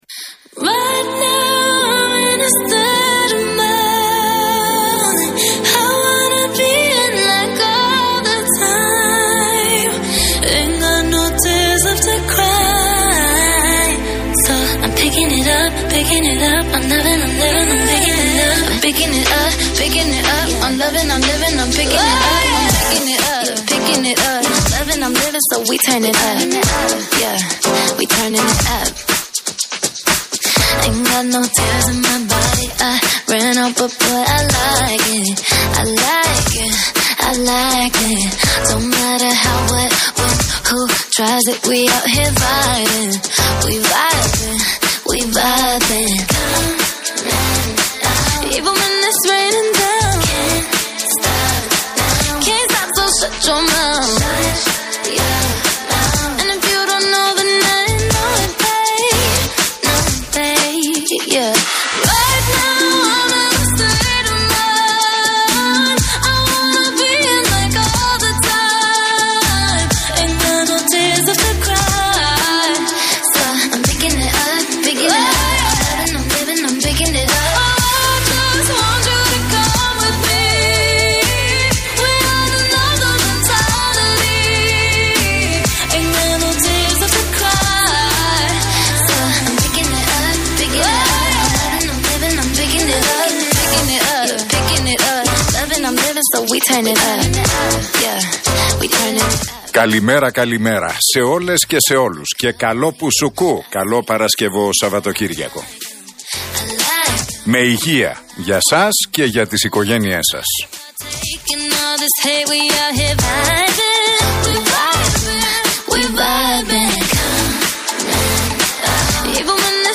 Ακούστε την εκπομπή του Νίκου Χατζηνικολάου στον Real Fm 97,8, την Παρασκευή 10 Δεκεμβρίου 2021.